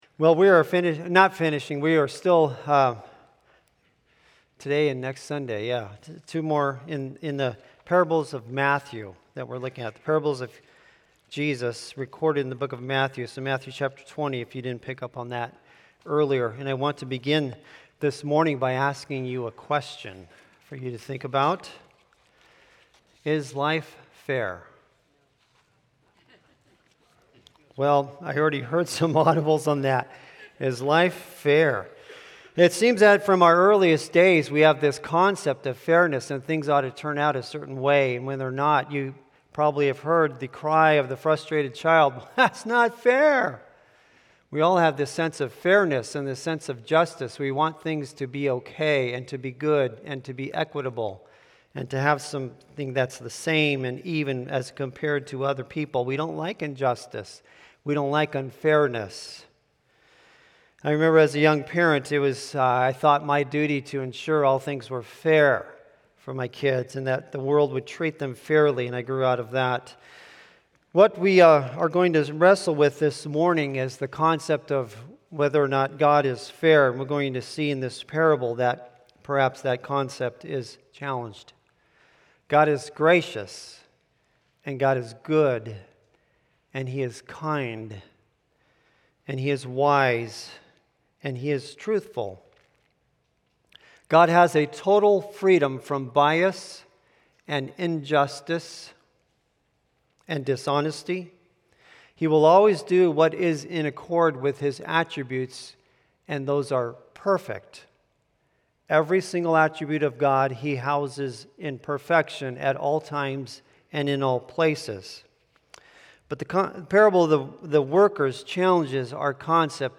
Sermons | Hope Community Church